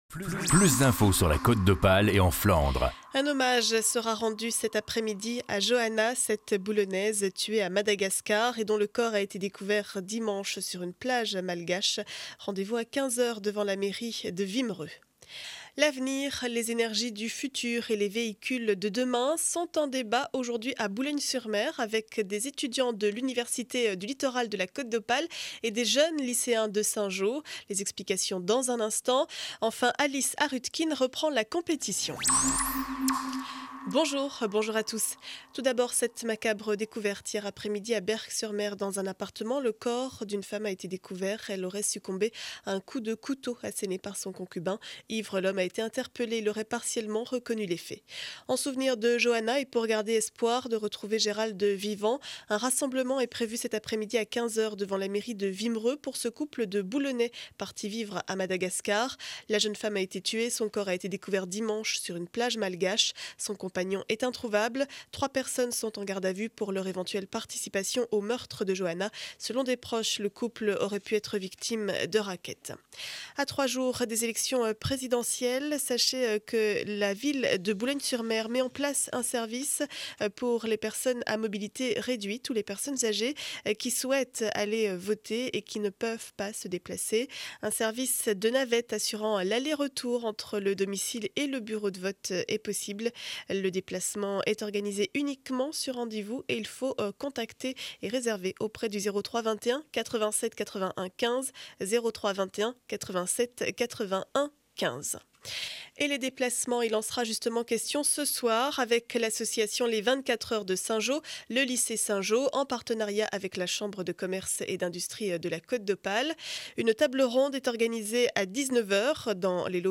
Journal du jeudi 19 avril 2012 7 heures 30 édition du Boulonnais.